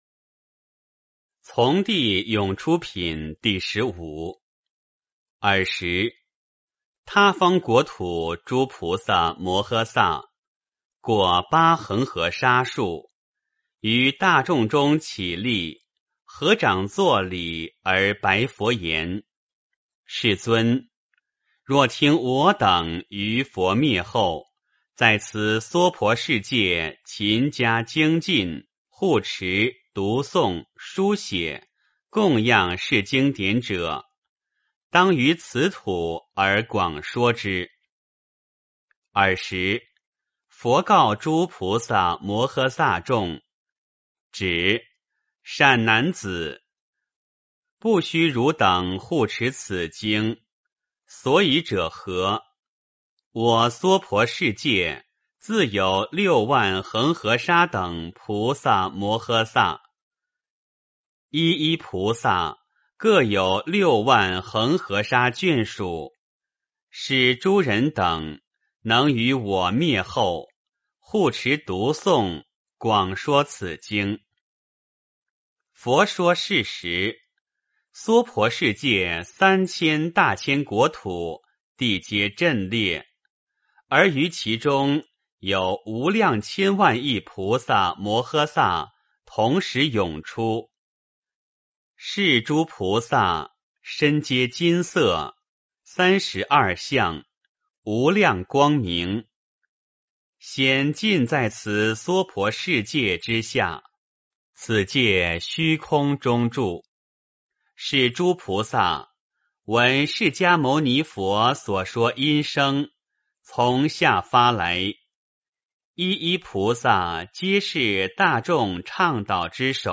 法华经-从地涌出品第十五 诵经 法华经-从地涌出品第十五--未知 点我： 标签: 佛音 诵经 佛教音乐 返回列表 上一篇： 佛说四辈经 下一篇： 发菩提心经论卷上 相关文章 金刚经-赞--圆光佛学院众法师 金刚经-赞--圆光佛学院众法师...